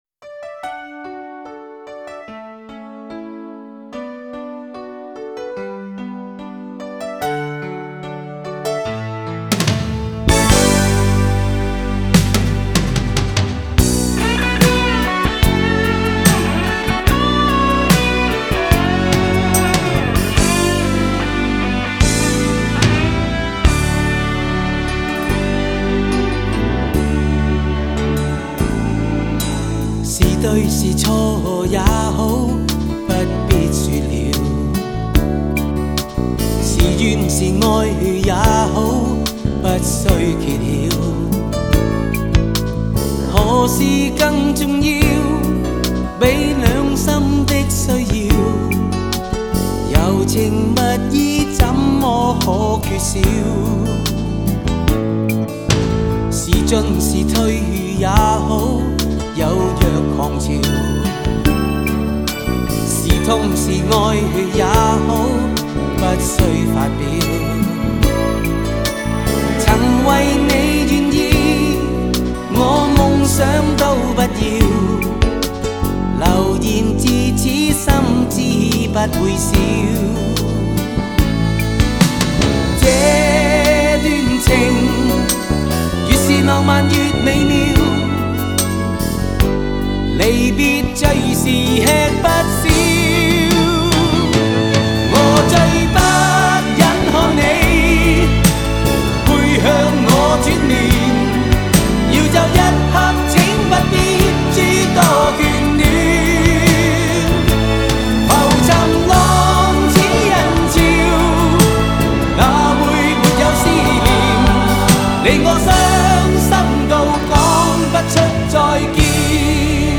深情的旋律和歌词